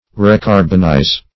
Search Result for " recarbonize" : The Collaborative International Dictionary of English v.0.48: Recarbonize \Re*car"bon*ize\ (r[-e]*k[aum]r"b[o^]n*[imac]z), v. t. (Metal.) To restore carbon to; as, to recarbonize iron in converting it into steel.
recarbonize.mp3